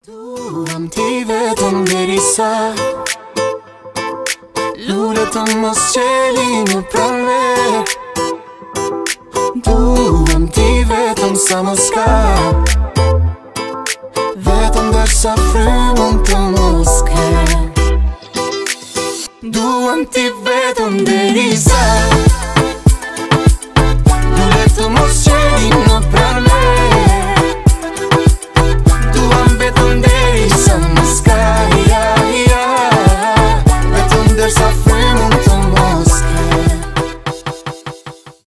дуэт , поп